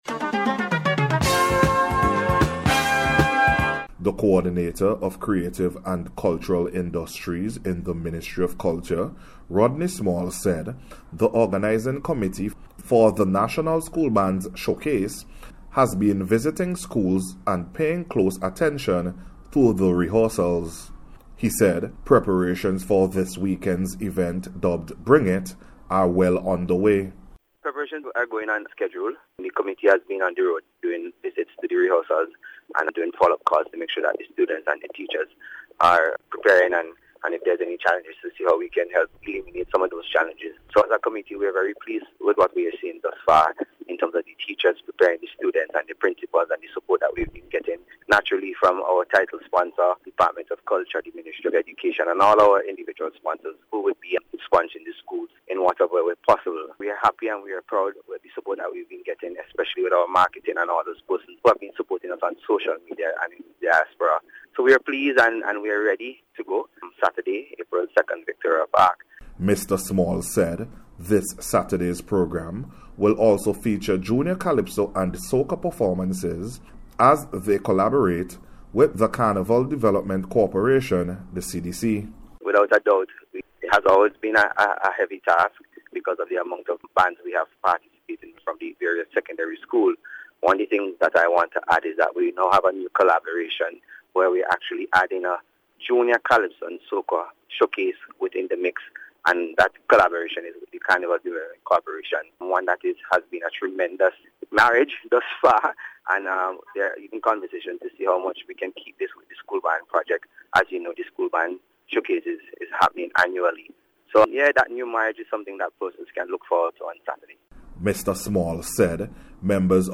NBC’s Special report for March 29th 2022